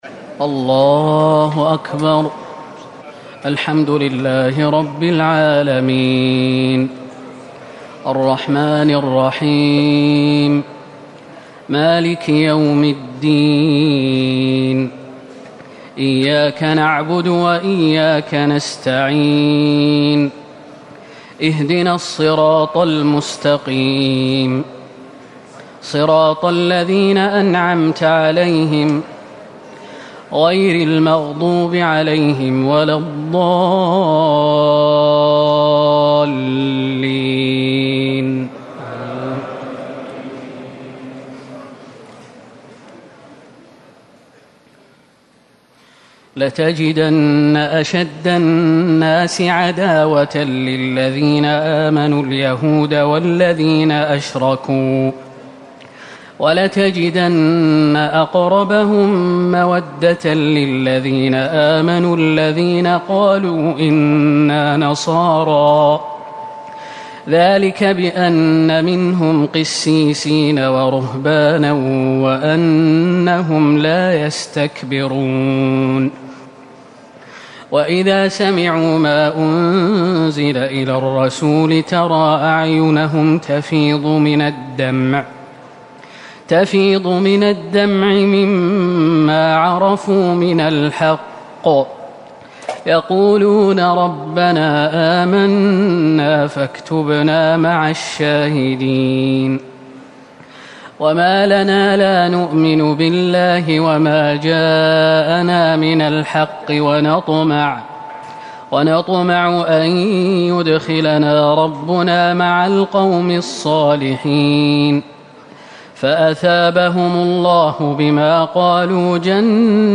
تهجد ٢٧ رمضان ١٤٤٠ من سورة المائدة ٨٢ - الأنعام ٥٧ > تراويح الحرم النبوي عام 1440 🕌 > التراويح - تلاوات الحرمين